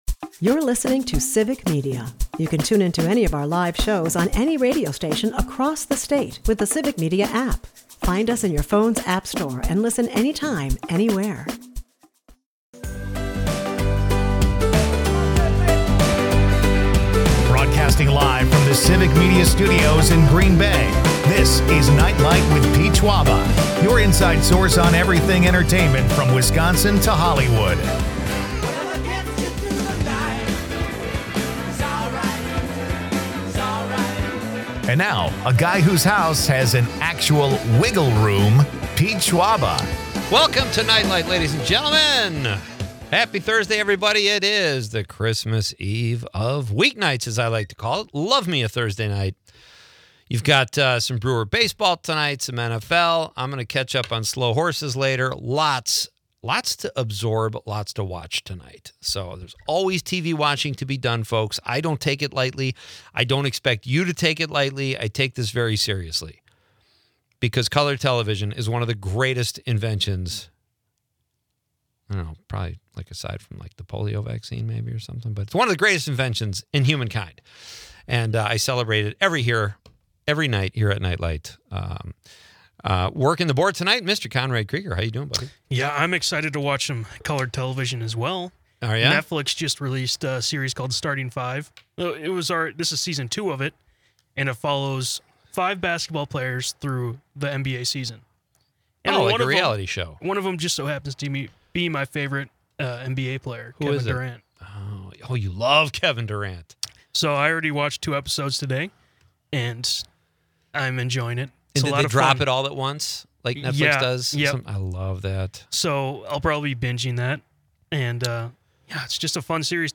Leave the stress of the day behind with entertainment news, comedy and quirky Wisconsin.